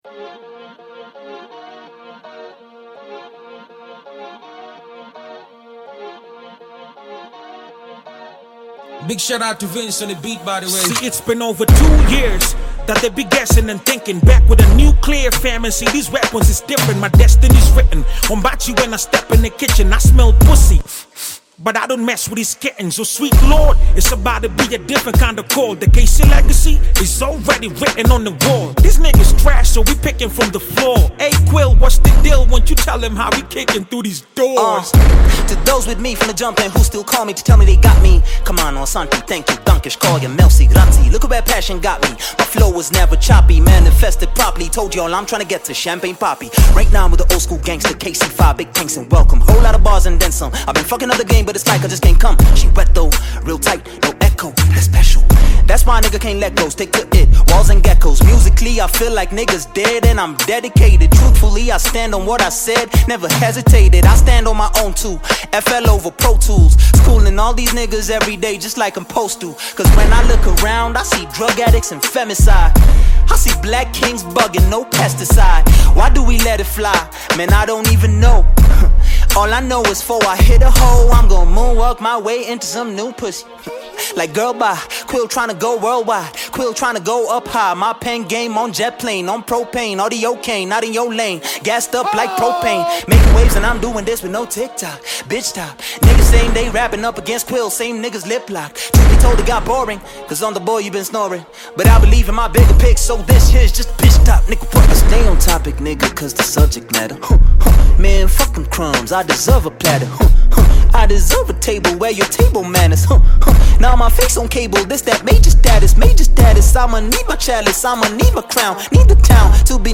Kenyan Hip Hop music
Hip Hop Kenyan music track